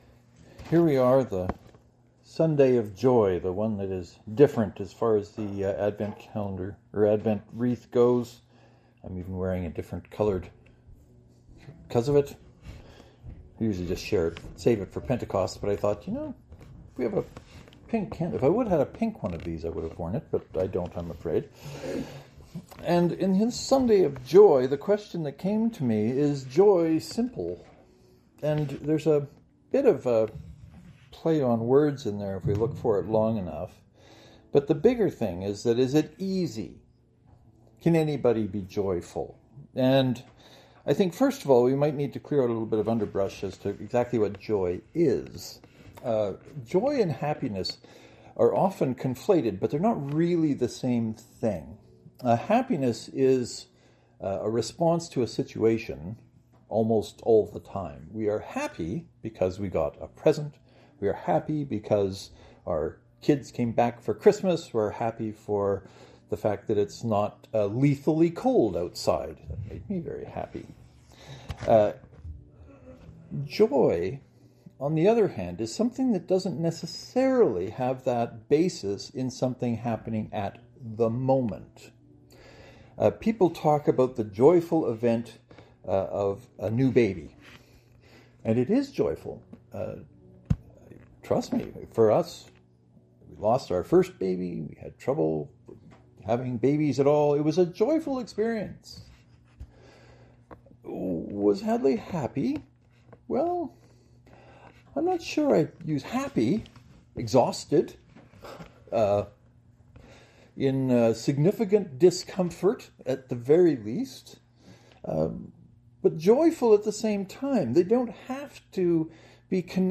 As I was reflecting on the third Sunday of Advent, the Sunday of joy, the question above became my sermon title.